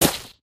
new_gravel1.ogg